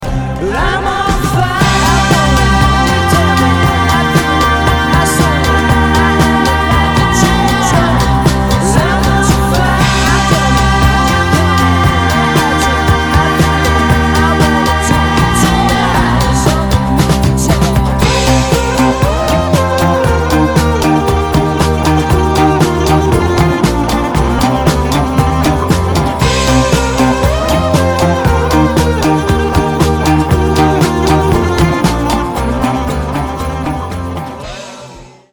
• Качество: 320, Stereo
громкие
мотивирующие
веселые
alternative
психоделический рок